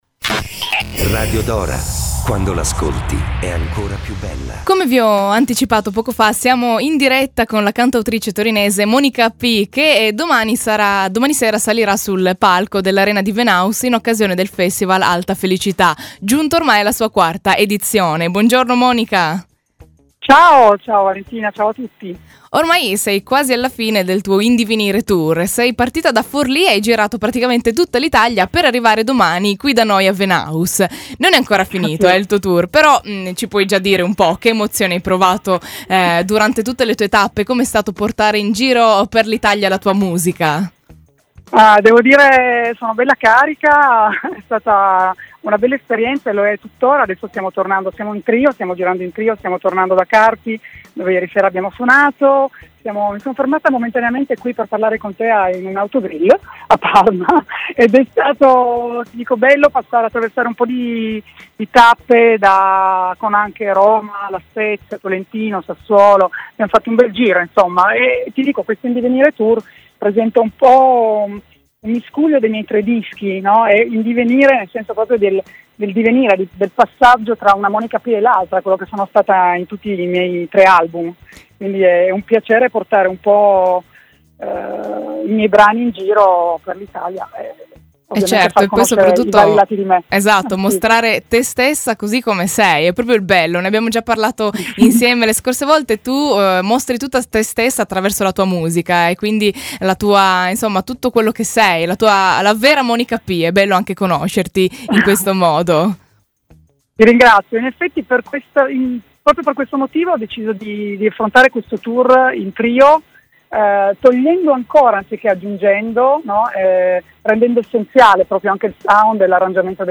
Radio Dora – intervista – 25 luglio 2019